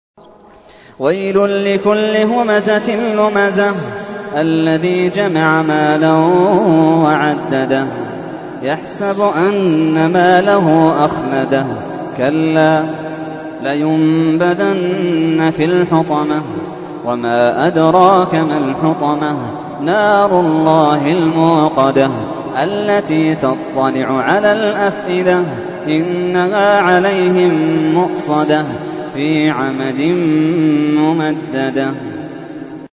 تحميل : 104. سورة الهمزة / القارئ محمد اللحيدان / القرآن الكريم / موقع يا حسين